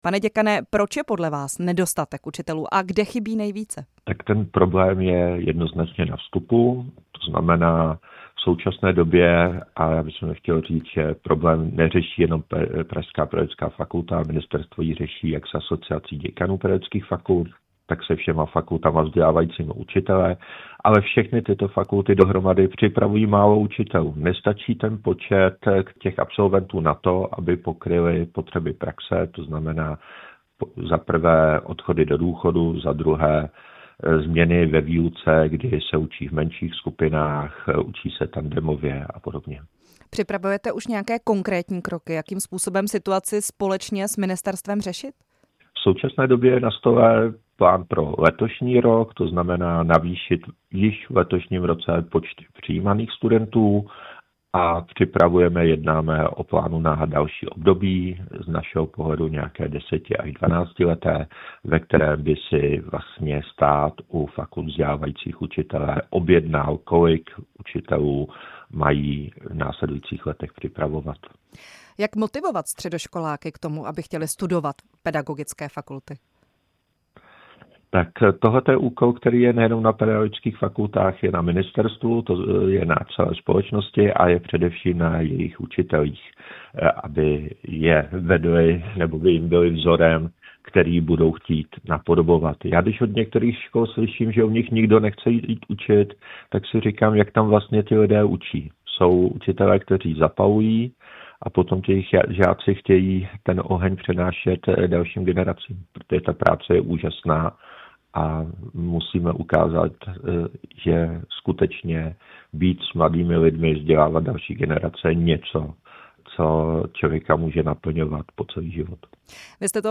ve vysílání Radia Prostor